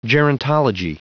Prononciation du mot gerontology en anglais (fichier audio)
Prononciation du mot : gerontology